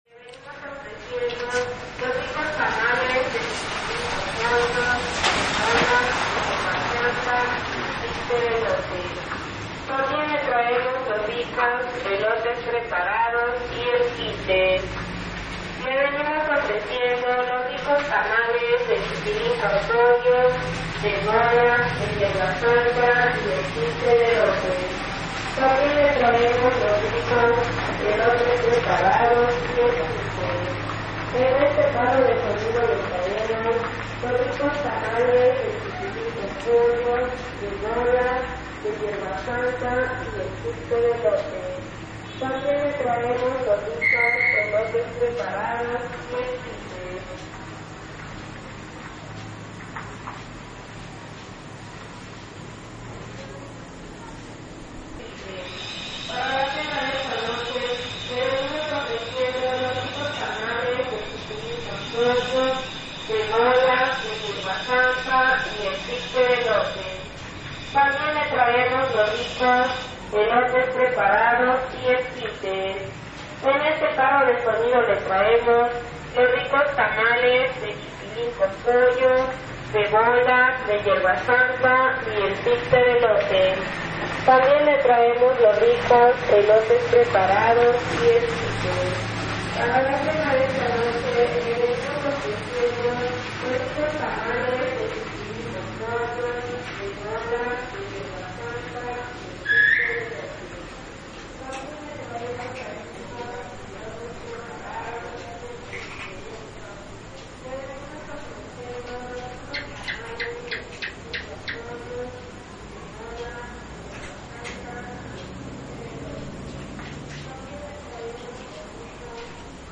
Anuncio para la cena de esta noche
El anuncio irrumpe la noche, invitándonos a degustar algunos de los tamales que ofrecen para la cena.
Lugar: Tuxtla Gutierrez, Chiapas; Mexico.
Equipo: Grabadora Sony ICD-UX80 Stereo